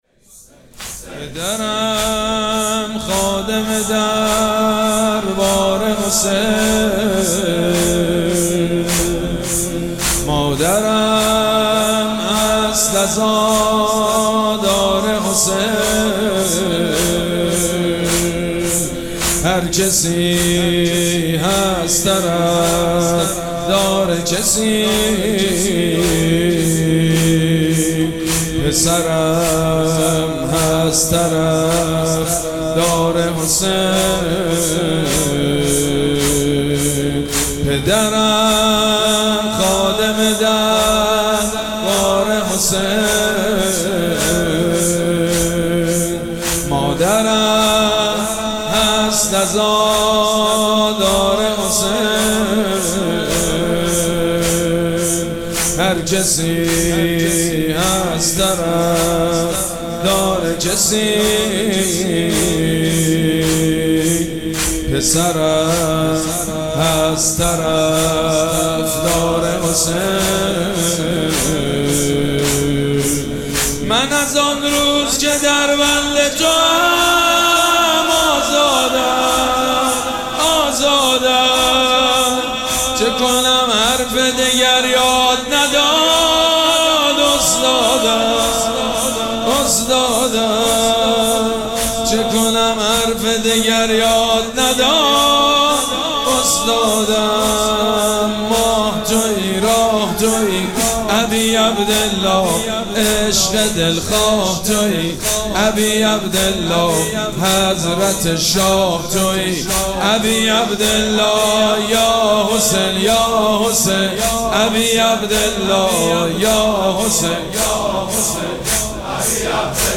مراسم عزاداری شب هفتم محرم الحرام ۱۴۴۷
مداح
حاج سید مجید بنی فاطمه